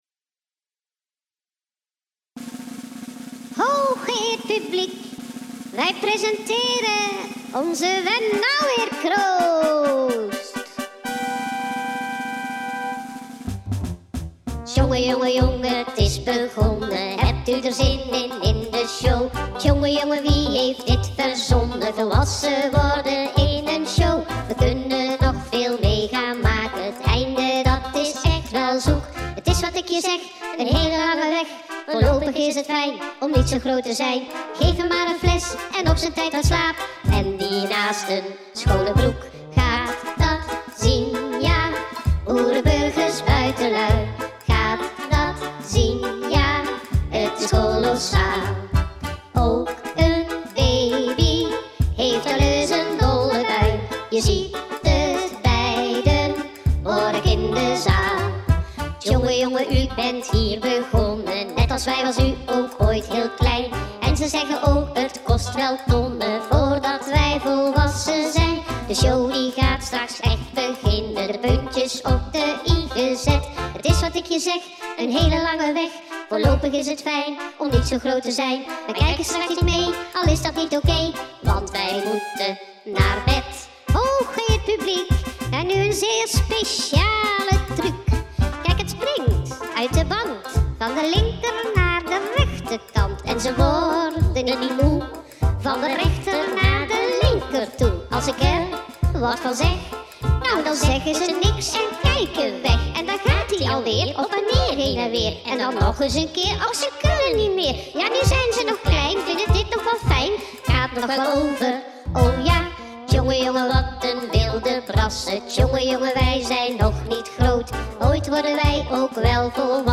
21ste Voorstelling 2016
Lied: Kermis
2016_kermislied.mp3